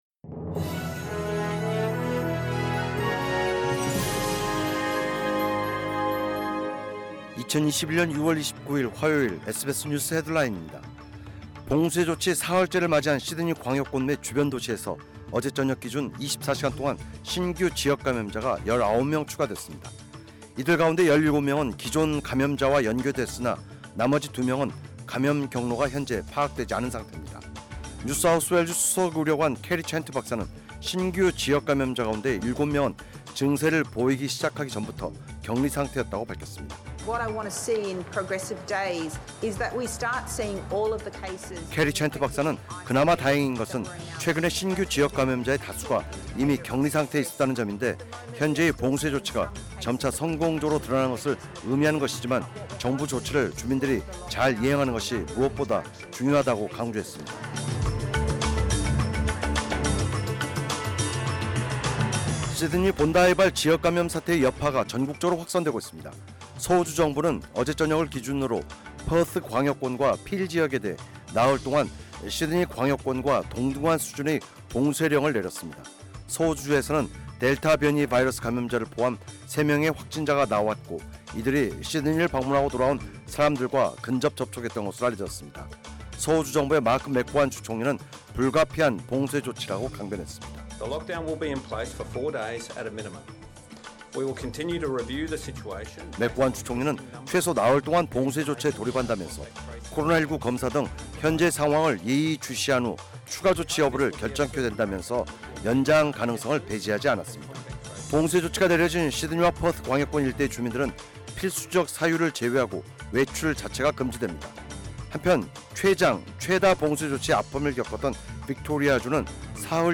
2021년 6월 29일 화요일 SBS 뉴스 헤드라인입니다.